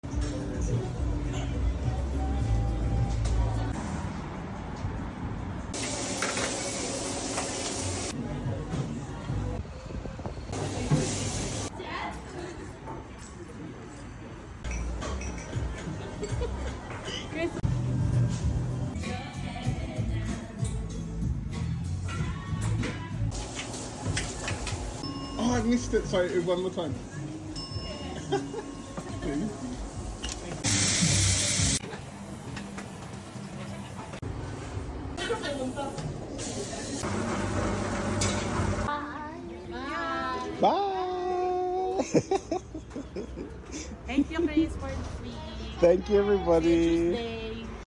I wish I’d recorded more laughter and banter, because they’re the main sounds at Tita (our staff are so hilarious and fun to work with).